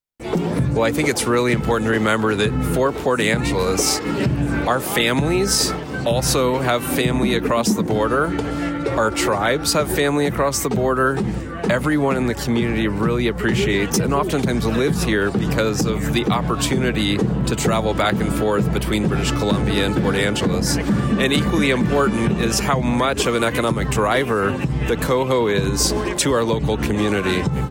Port Angeles – Despite the cold, the mood was warm and festive Thursday afternoon at the Coho Ferry terminal where hundreds of people showed up with signs and banners welcoming the ferry and its Canadian passengers back to Port Angeles after the boat’s annual winter break.
Here’s Port Angeles City Manager Nathan West.